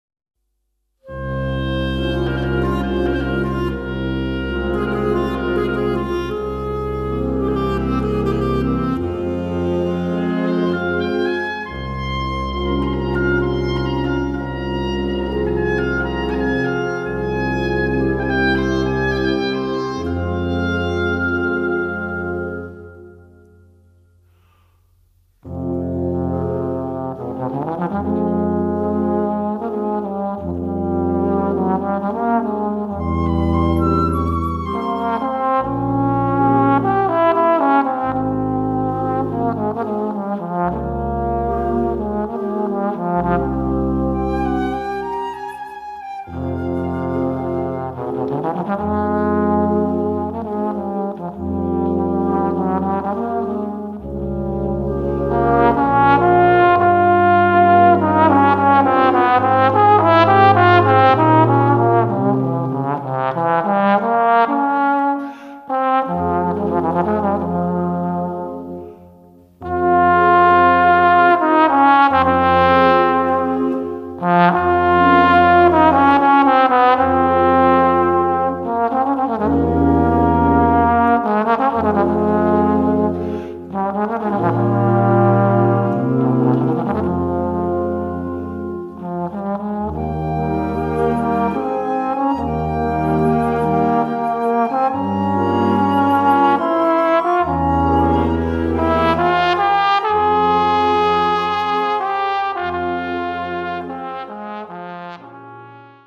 Voicing: Viola and Brass Band